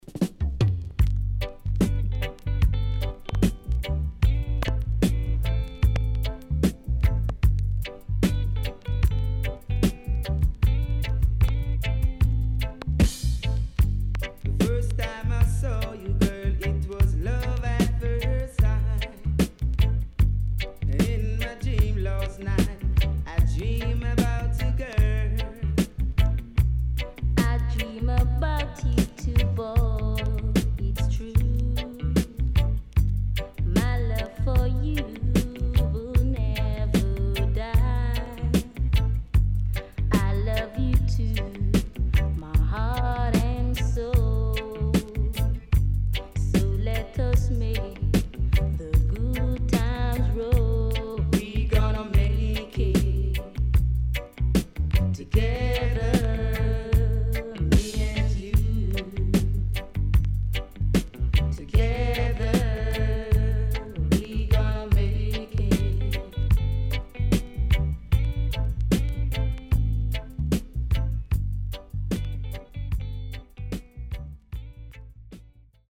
HOME > REISSUE USED [DANCEHALL]
SIDE A:プレス起因により所々プチノイズ入ります。